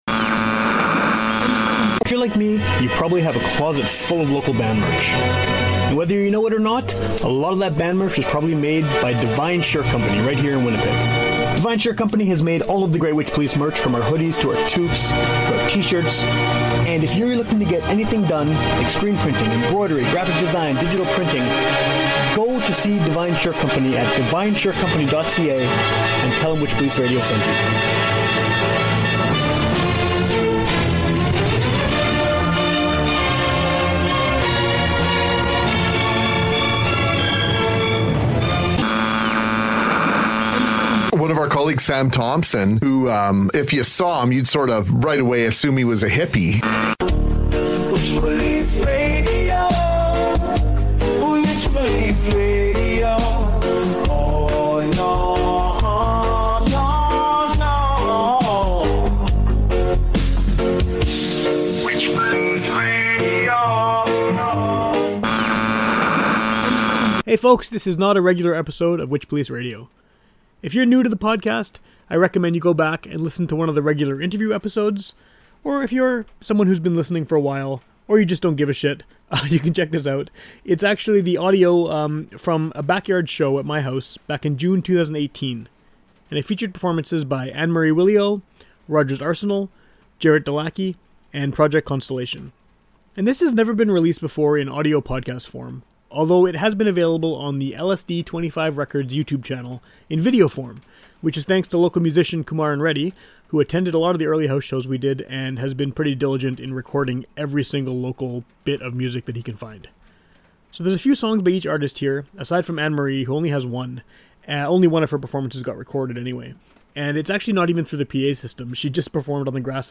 Here's the audio from a backyard show
she just performed on the grass in the yard with her accordion!